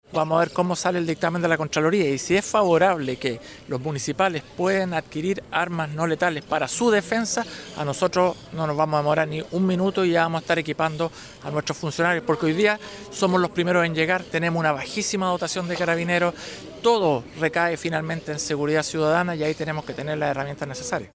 El alcalde de Lo Barnechea y presidente de la Asociación de Municipios Zona Oriente, Felipe Alessandri, sostuvo que siempre los primeros en llegar son los inspectores municipales.